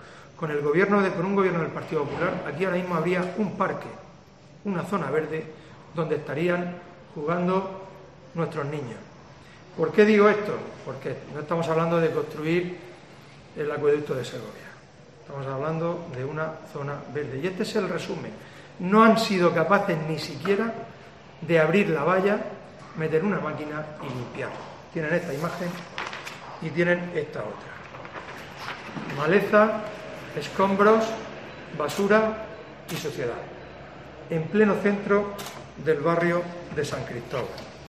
AUDIO: Fulgencio Gil, portavoz del PP en Lorca